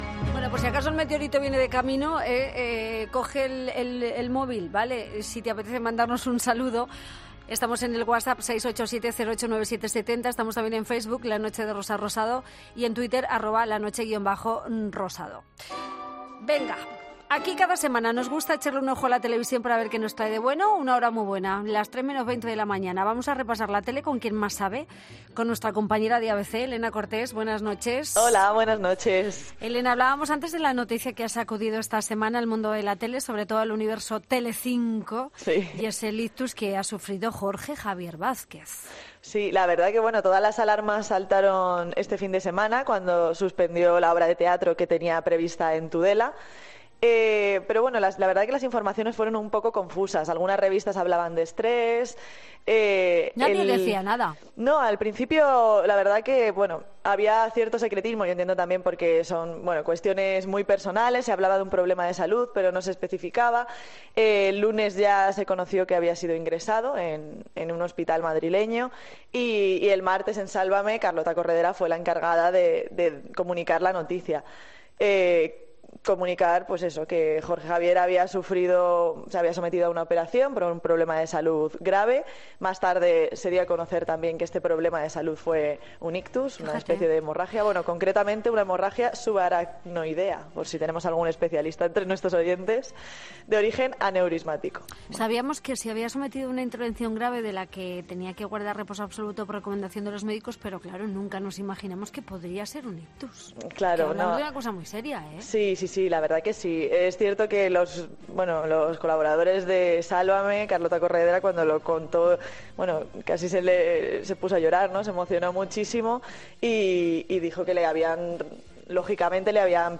AUDIO: Hablamos con la experta en televisión para conocer lo nuevo que trae.